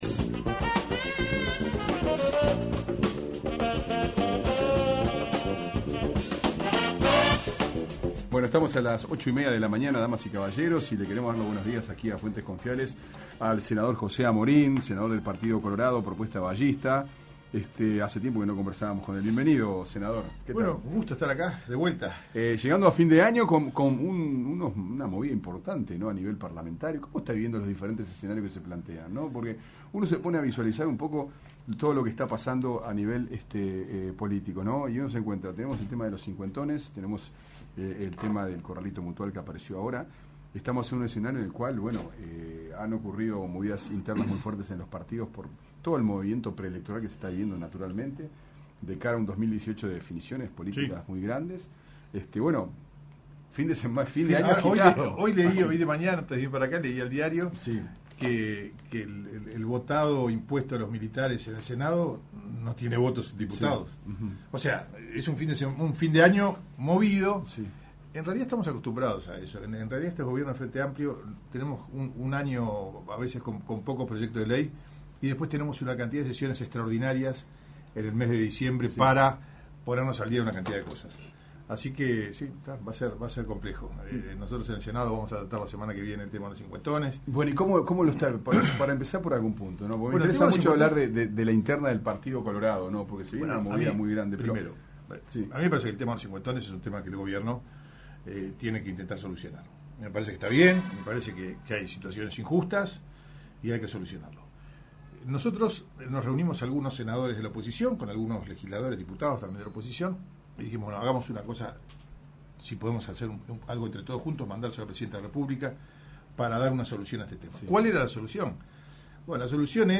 Fuentes Confiables recibió al Senador José Amorín Batlle con varios temas políticos sobre la mesa.